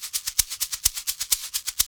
Index of /90_sSampleCDs/Houseworx/02 Percussion Loops